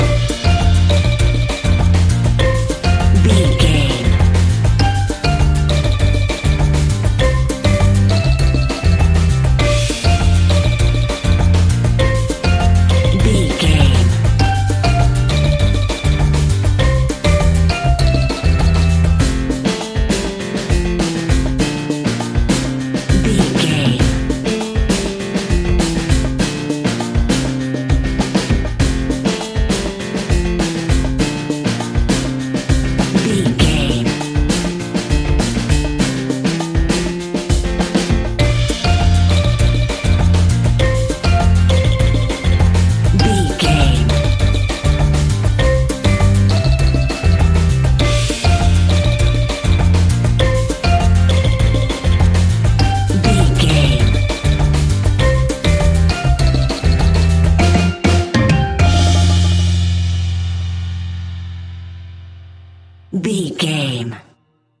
Uplifting
Ionian/Major
steelpan
drums
brass
guitar